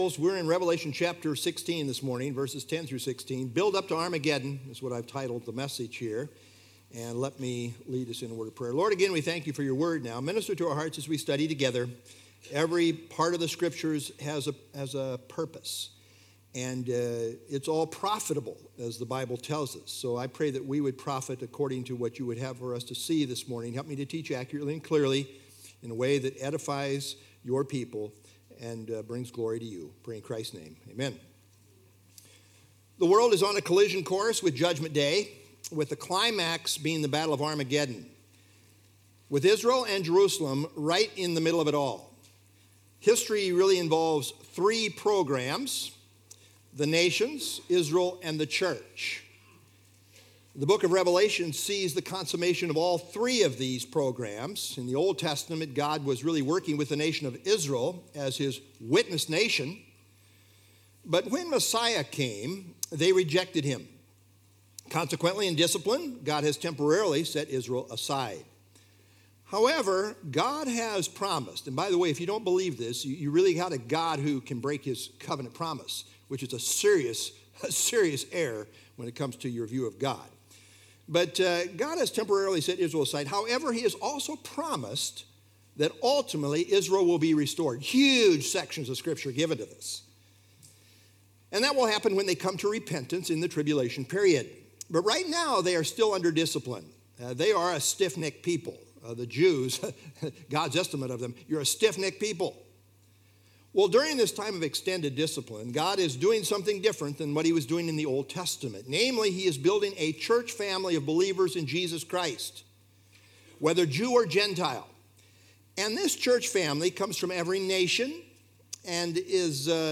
Download FilesRev 16 10-16 Sermon - April 19 2026Revelation 16_10-16